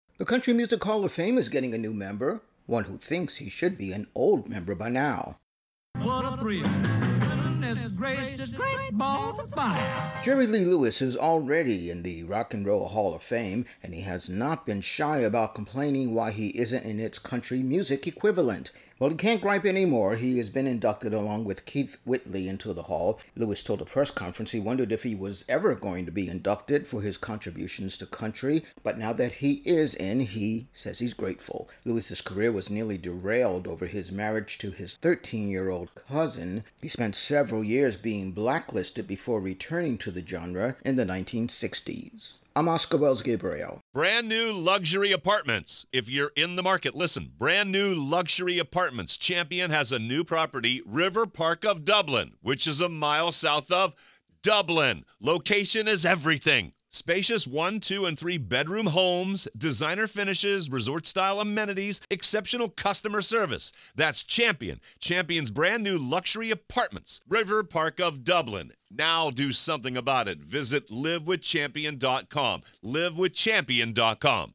Intro+wrap on Jerry Lee Lewis' induction into the Country Music Hall of Fame